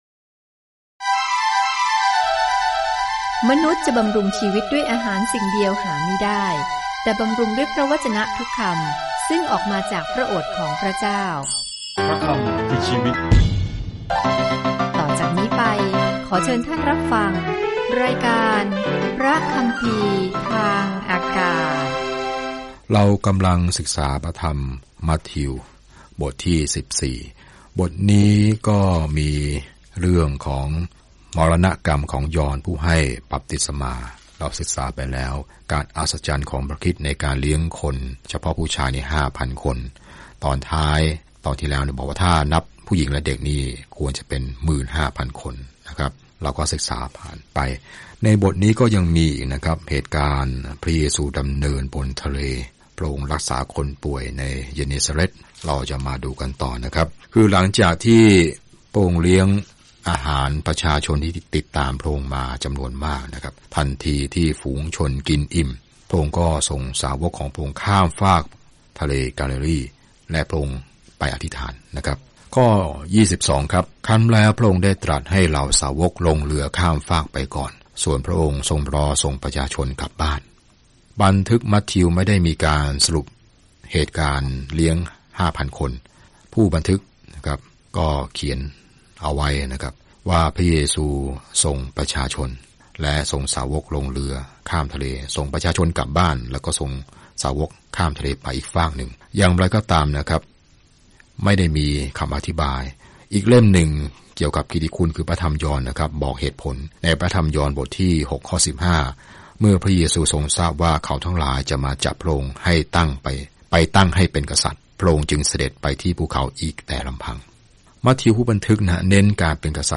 มัทธิวพิสูจน์ให้ผู้อ่านชาวยิวเห็นข่าวดีว่าพระเยซูคือพระเมสสิยาห์ของพวกเขาโดยแสดงให้เห็นว่าพระชนม์ชีพและพันธกิจของพระองค์ทำให้คำพยากรณ์ในพันธสัญญาเดิมเกิดสัมฤทธิผลอย่างไร เดินทางทุกวันผ่านมัทธิวในขณะที่คุณฟังการศึกษาด้วยเสียงและอ่านข้อที่เลือกจากพระวจนะของพระเจ้า